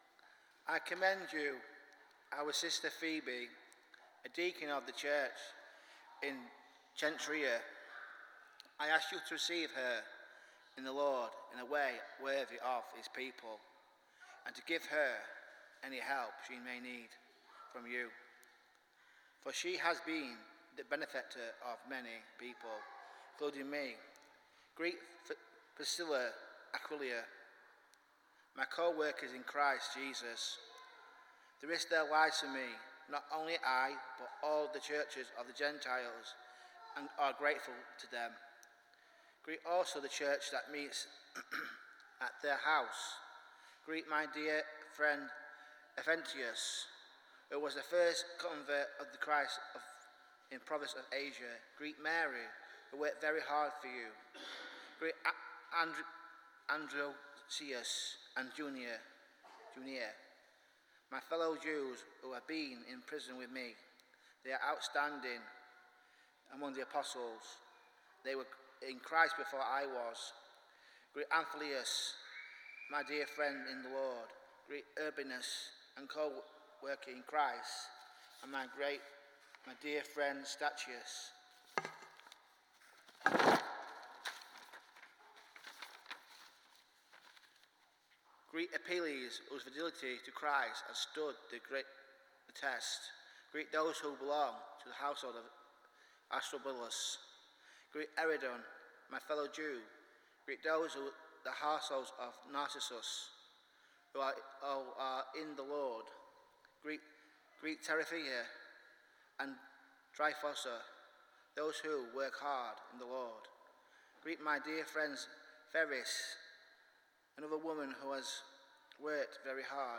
Passage: Romans 16:1-16 Service Type: Sunday Morning